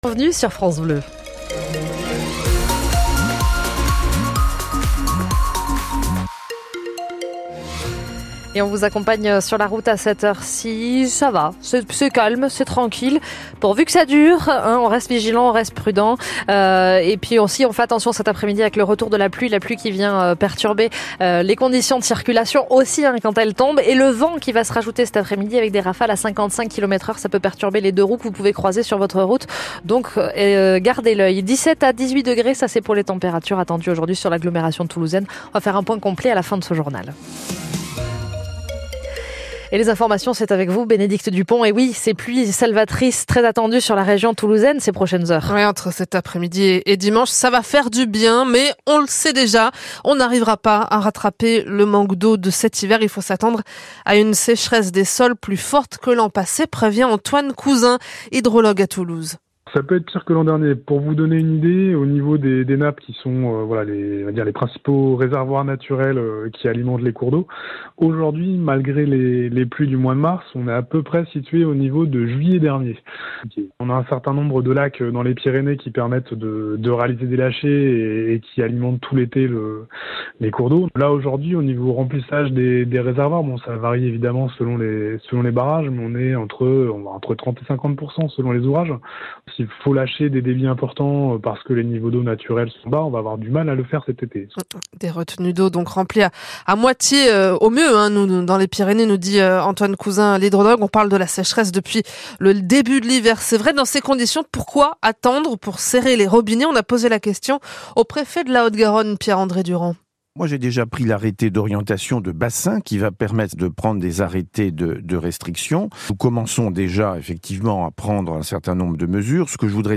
Ecoutez le flash info de 14h du mercredi 12 avril 2023 avec l’interview d’AC D’EAU et de M. Pierre-André Durand Préfet de la Haute-Garonne par France Bleu Occitanie.